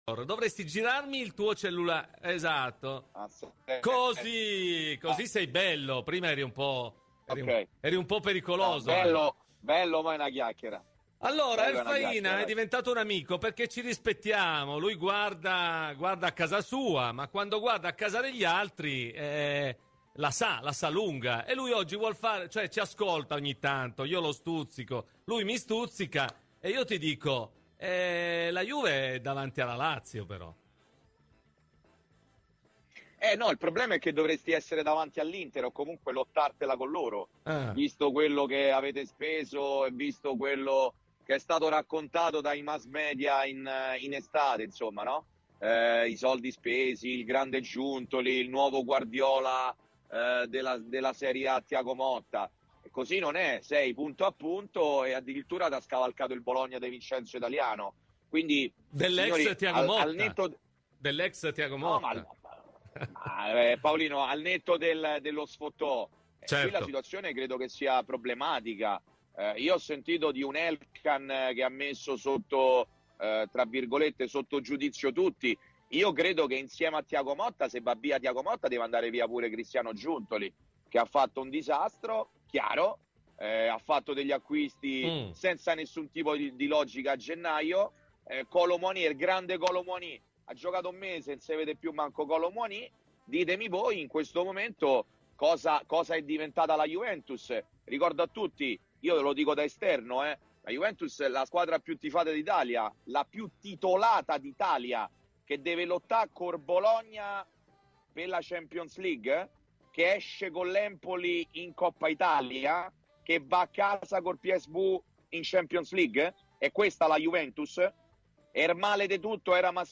Ospite di “Cose di Calcio” su Radio Bianconera